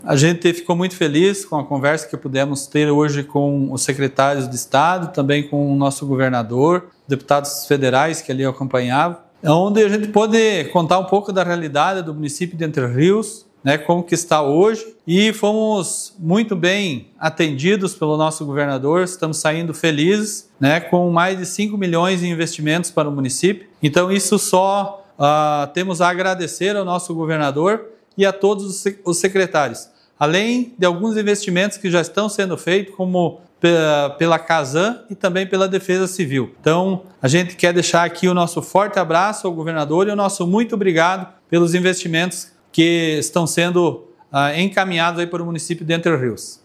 Após a conversa individual com o governador Jorginho Mello, o prefeito de Entre Rios, Evandro Antonio dos Passos, destacou mais de R$ 5 milhões em investimentos no município, além de investimentos em Proteção e Defesa Civil: